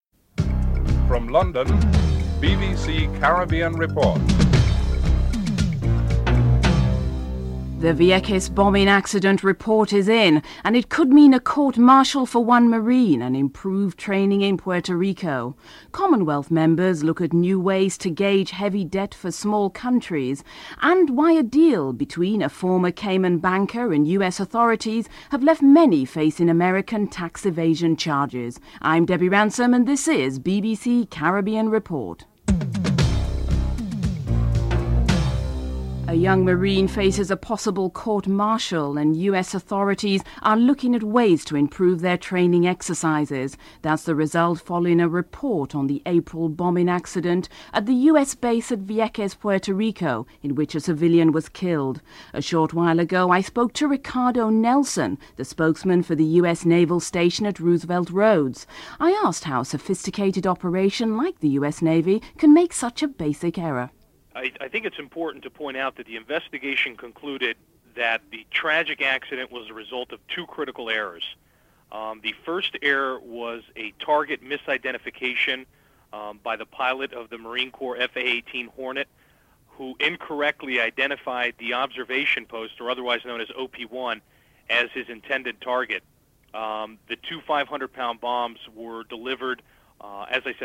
1. Headlines with anchor